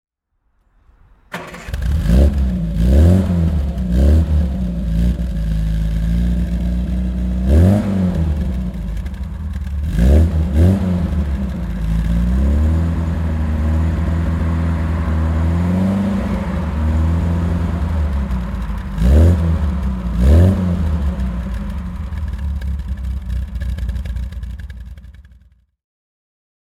Triumph Spitfire 4 Mk II (1967) - Starten und Leerlauf
Triumph_Spitfire_4_Mk_II_1967.mp3